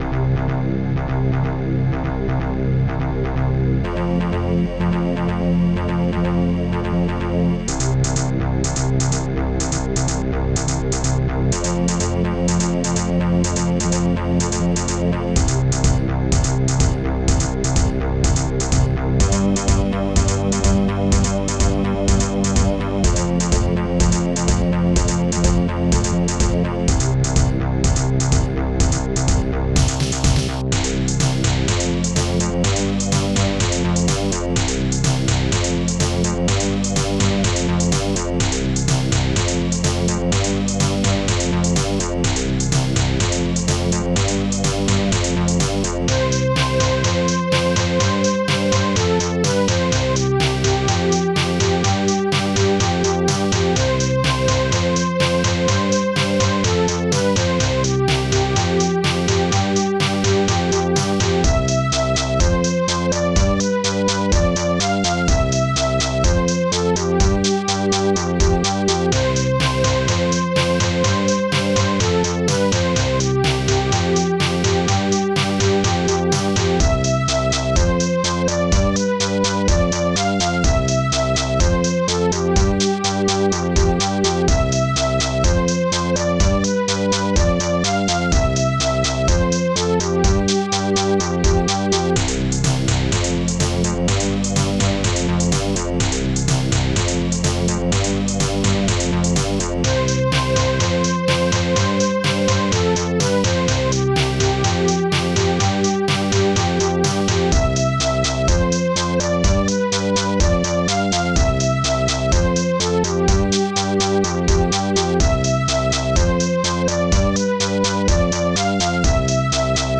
Instruments hihat2 bassdrum3 funbass nightmare popsnare2 shaker strings3 blast